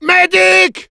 Commandes vocales du Demoman - Official TF2 Wiki | Official Team Fortress Wiki
Demoman_medic01_fr.wav